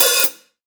Tuned hi hats Free sound effects and audio clips
• Focused Open High-Hat Sound A Key 05.wav
Royality free open high-hat tuned to the A note. Loudest frequency: 9003Hz
focused-open-high-hat-sound-a-key-05-FA8.wav